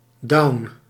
Ääntäminen
IPA: /dɑu̯n/